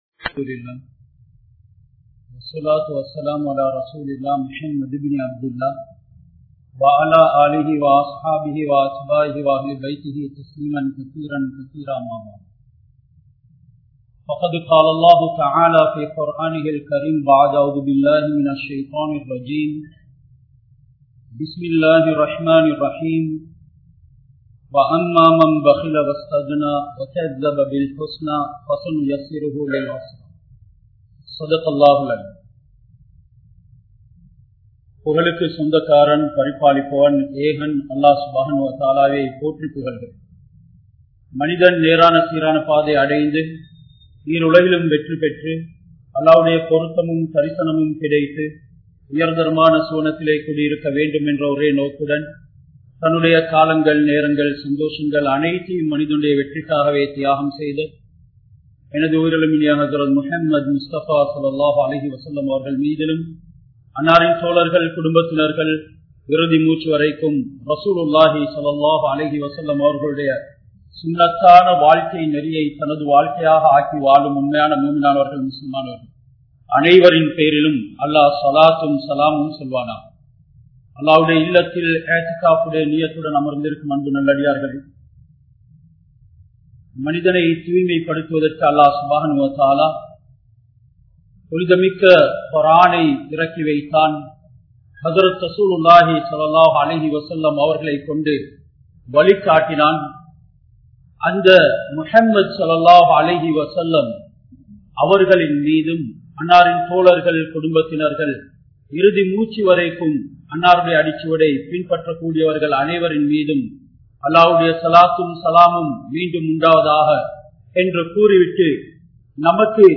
Kanjanaaha Valaatheerhal (கஞ்சனாக வாழாதீர்கள்) | Audio Bayans | All Ceylon Muslim Youth Community | Addalaichenai
Colombo 12, Aluthkade, Muhiyadeen Jumua Masjidh